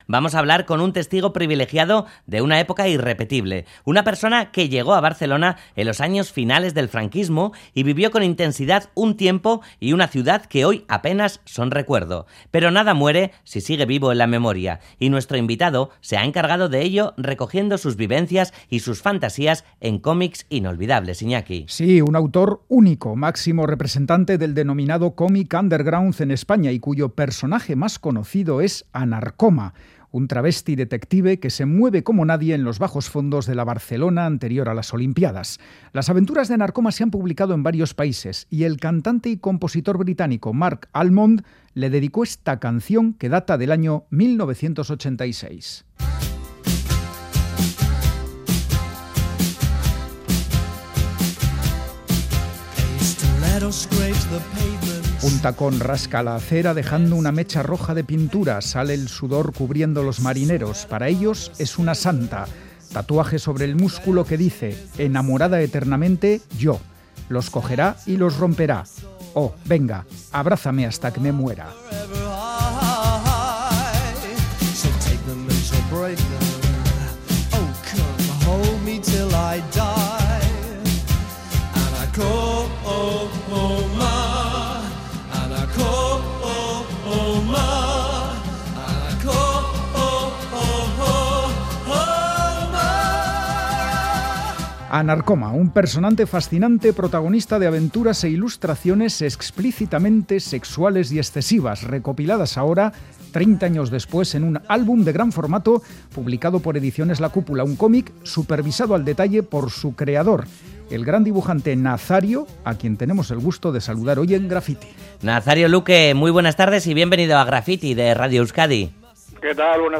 Hablamos con Nazario Luque, el veterano dibujante autor de cómics como "Anarcoma", que se acaba de reeditar en un volumen de gran formato